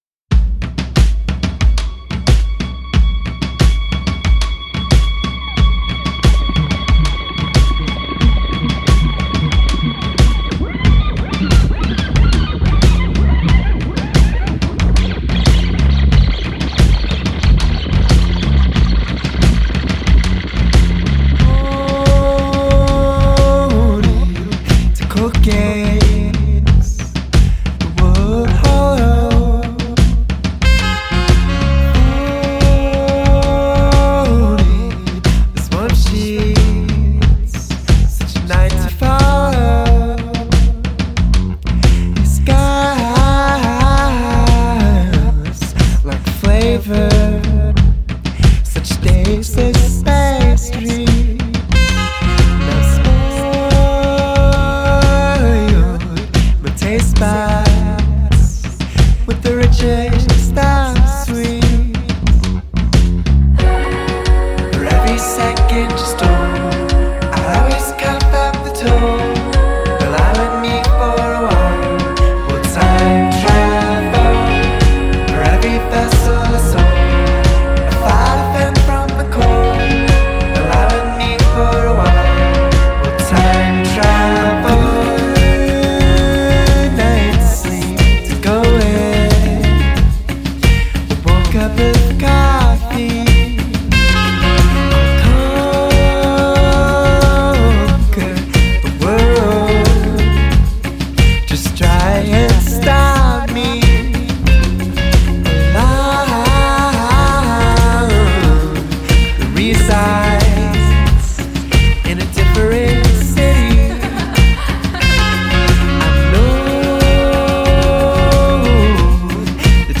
indie-pop outfit
Focusing on rhythm and melody